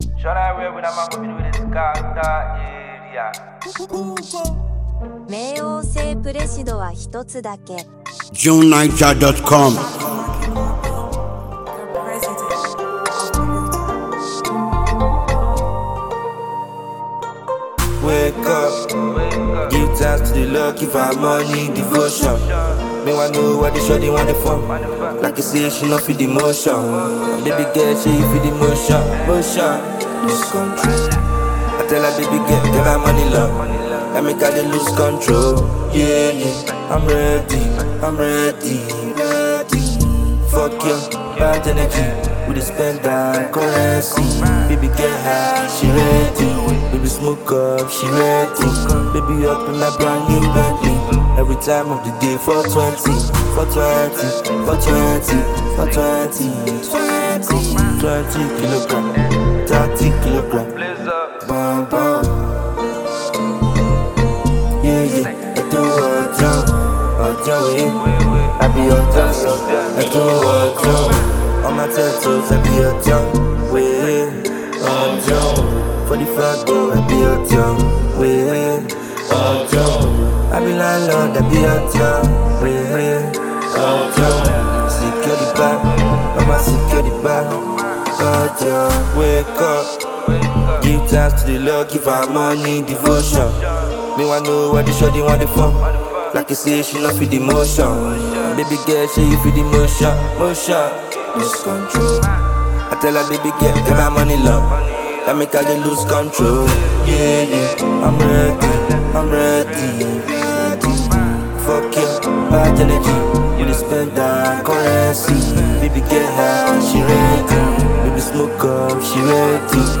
street pop